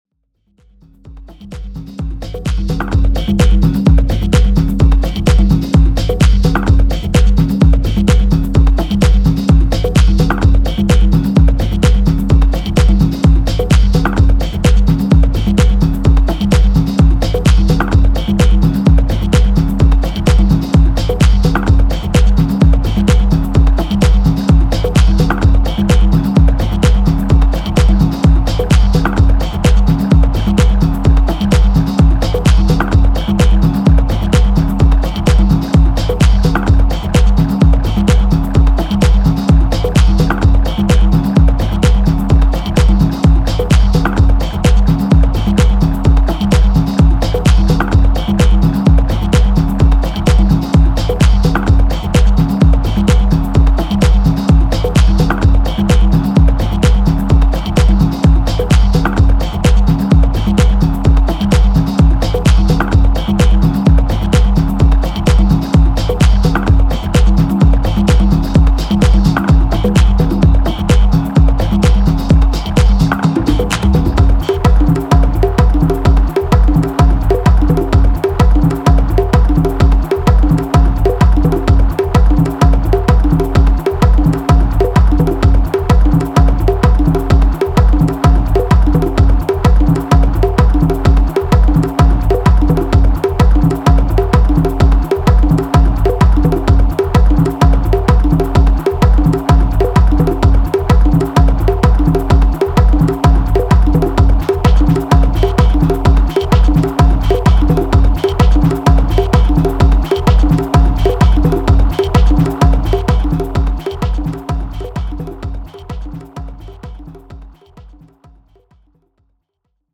リヴァーブの効いたトライバル・パーカッションがノイズ渦巻く空間を掻き乱す